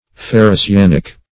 Ferrocyanic - definition of Ferrocyanic - synonyms, pronunciation, spelling from Free Dictionary
Ferrocyanic \Fer`ro*cy*an"ic\, a. [Ferro- + cyanic: cf. F.